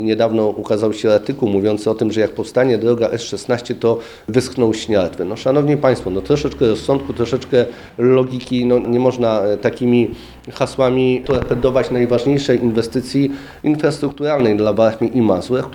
Jak przekonywał 7 sierpnia na konferencji prasowej poseł Prawa i Sprawiedliwości Wojciech Kossakowski, wokół planowanej inwestycji narosło wiele mitów, a jest ona kluczowa dla rozwoju regionu.